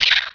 pain1.wav